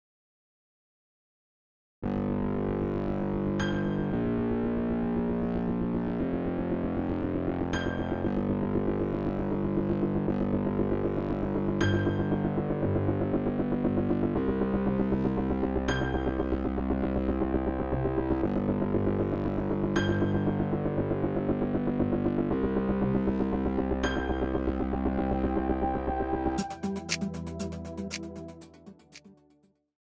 Instrumentation: Viola Solo